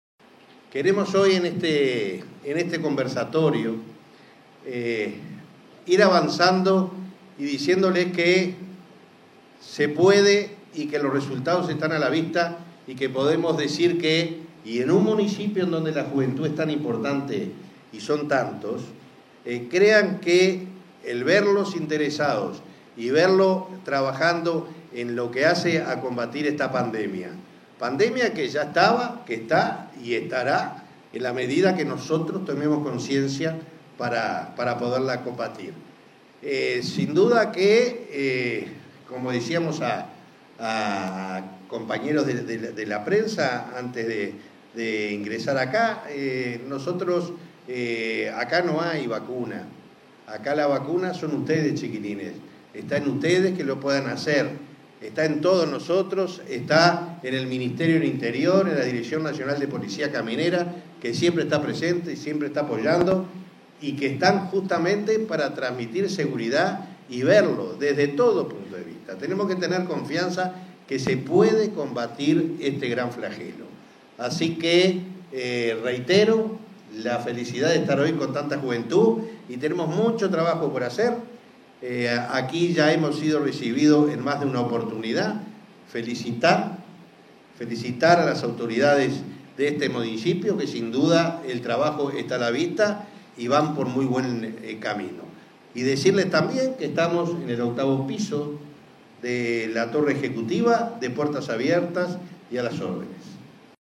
Palabras del presidente de Unasev, Alejandro Draper
En la ceremonia participó el director de la Unasev.